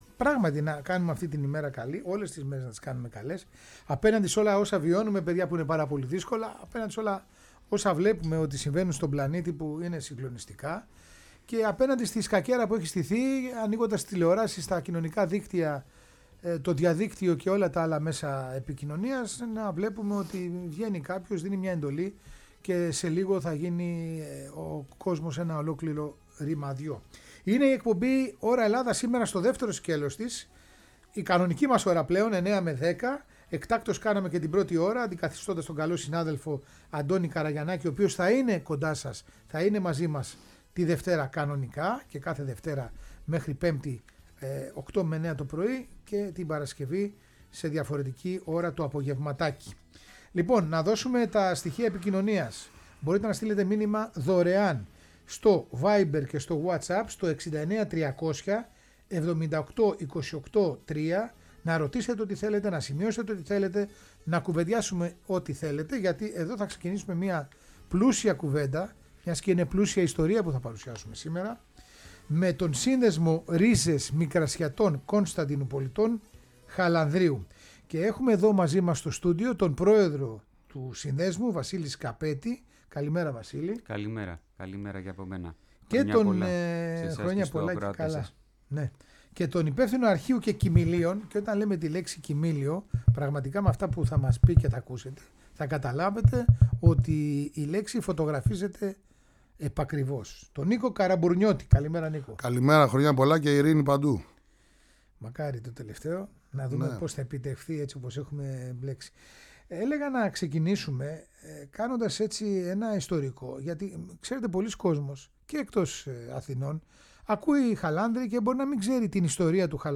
Αυτές τις ιστορίες προσφυγιάς συζητάμε ζωντανά στο στούντιο της Φωνής της Ελλάδας
Συνεντεύξεις